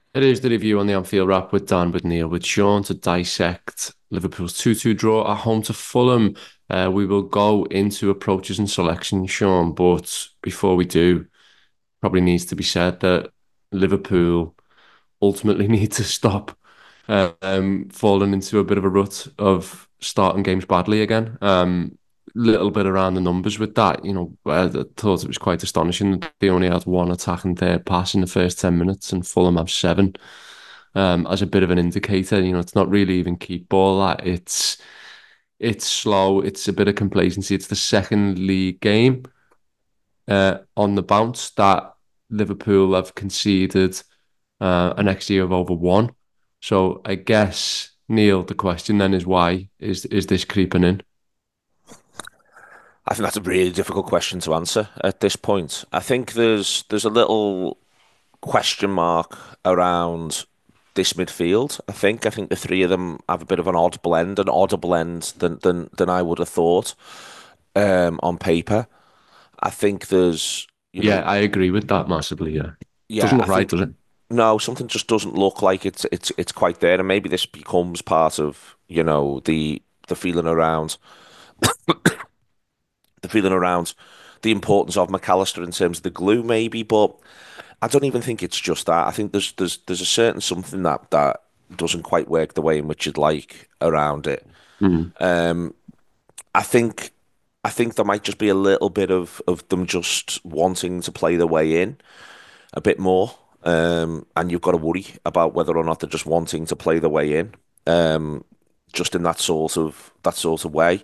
Below is a clip from the show – subscribe to The Anfield Wrap for more review chat around Liverpool 2 Fulham 2…